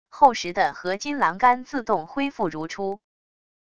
厚实的合金栏杆自动恢复如初wav音频